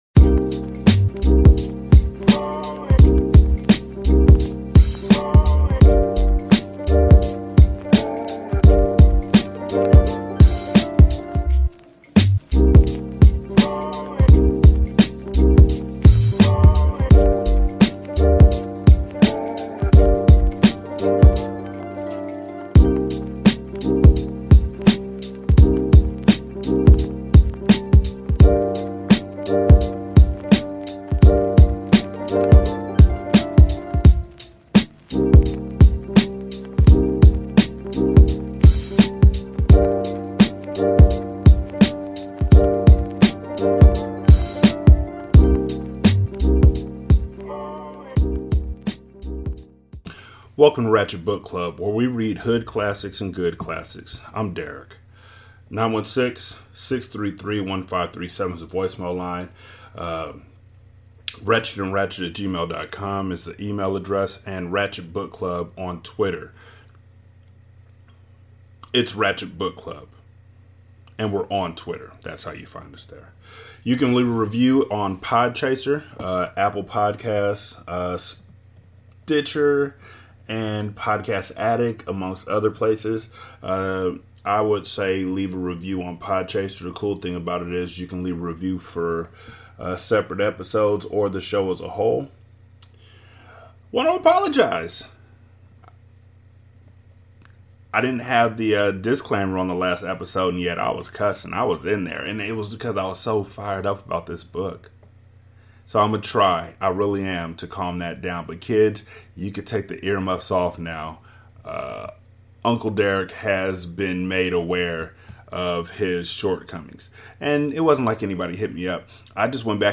Welcome to Ratchet Book Club, where we read Good Classics and Hood Classics alike.